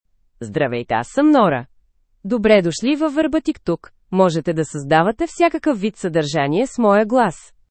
NoraFemale Bulgarian AI voice
Nora is a female AI voice for Bulgarian (Bulgaria).
Voice sample
Female
Nora delivers clear pronunciation with authentic Bulgaria Bulgarian intonation, making your content sound professionally produced.